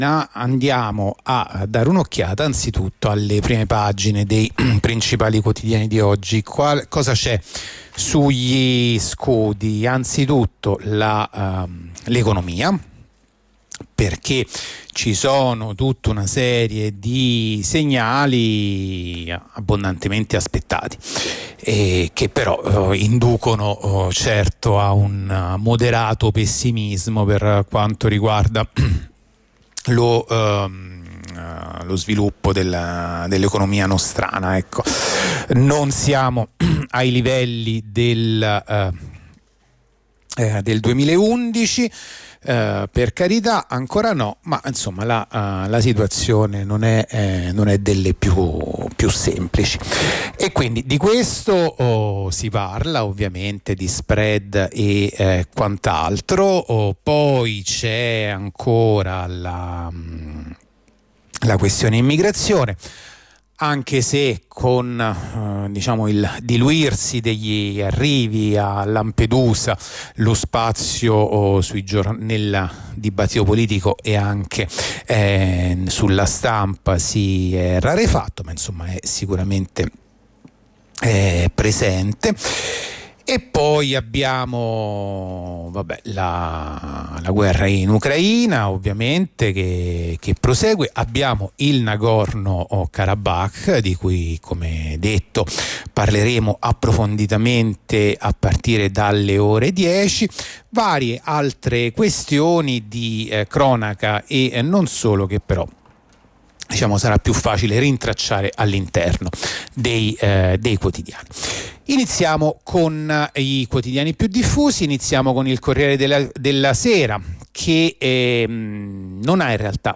La rassegna stampa di radio onda rossa andata in onda venerdì 29 settembre 2023